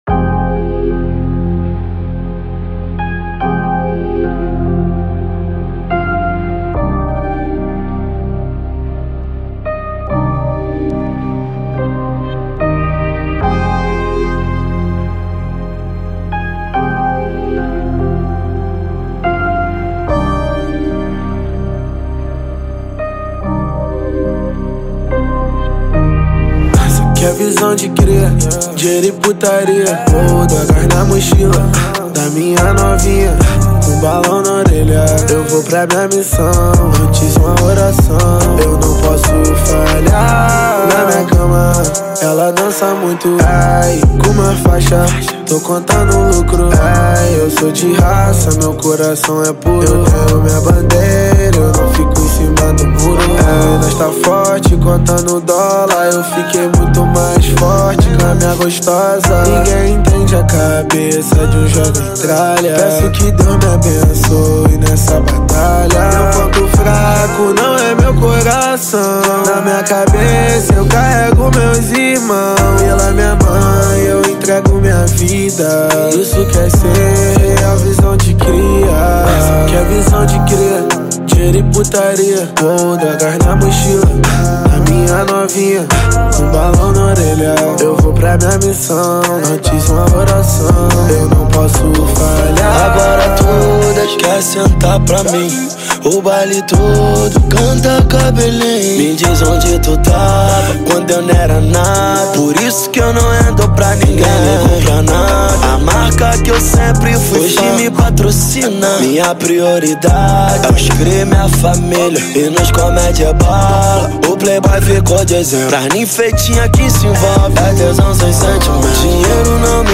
2024-04-08 11:24:36 Gênero: Trap Views